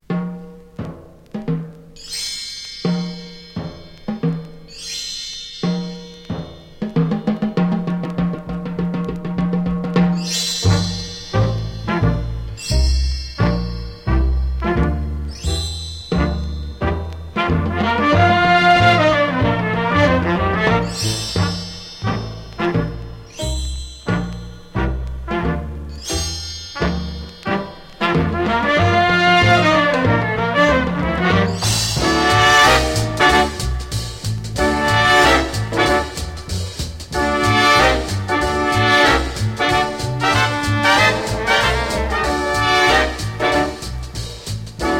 舞台音楽ということで、耳馴染みのあるメロディや叙情豊かな印象が響きます。
Jazz, Stage & Screen　France　12inchレコード　33rpm　Stereo